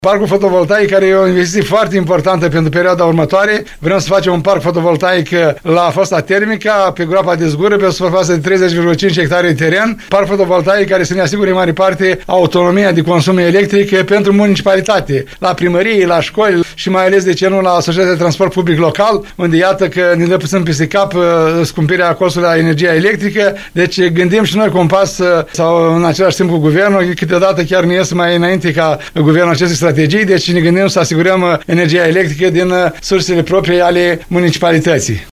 Primarul municipiului Suceava ION LUNGU a precizat postului nostru de radio că energia produsă ar putea fi folosită la alimentarea autobuzelor electrice TPL și a unităților de învățământ.